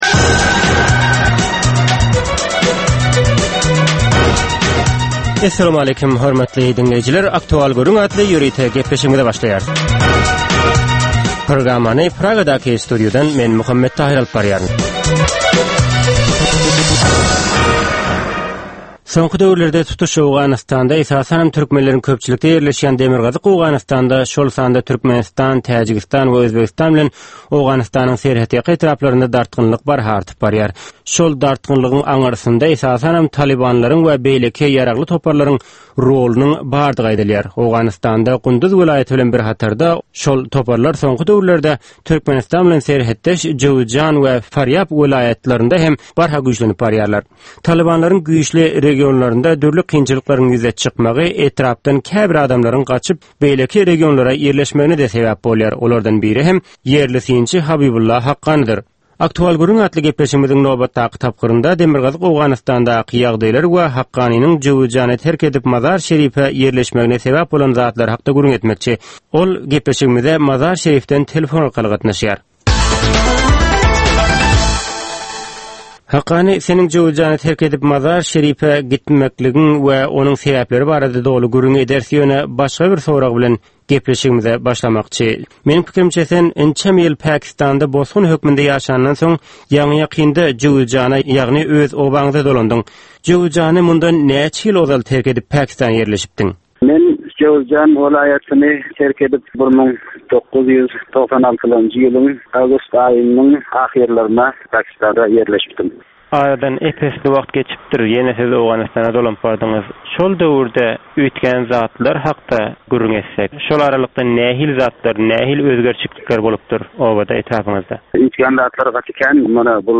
Hepdäniň dowamynda Türkmenistanda ýa-da halkara arenasynda ýüze çykan, bolup geçen möhüm wakalar, meseleler barada anyk bir bilermen ýa-da synçy bilen geçirilýän ýörite söhbetdeşlik. Bu söhbetdeşlikde anyk bir waka ýa-da mesele barada synçy ýa-da bilermen bilen gürrüňdeşlik geçirilýär we meseläniň dürli ugurlary barada pikir alyşylýar.